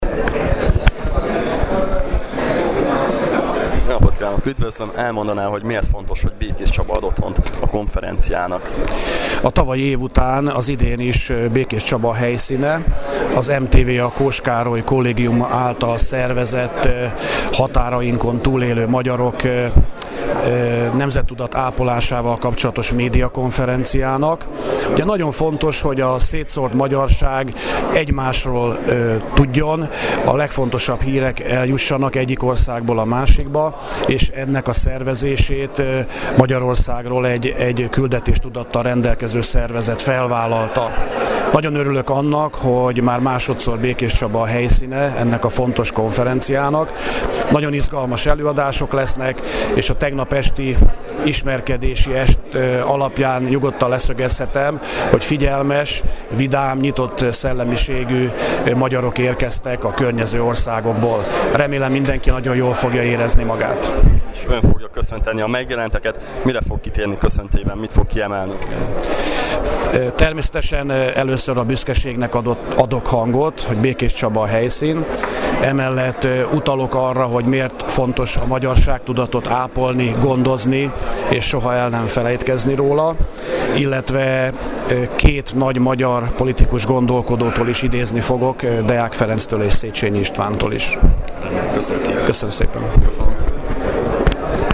Ebben az évben is Békéscsaba ad otthont az MTVA Kós Károly Kollégiuma által szervezett konferenciának. A határainkon túl élő magyarok nemzettudatápolásával kapcsolatos médiakonferencia köszöntőjét megelőzően Szarvas Péter polgármester nyilatkozott.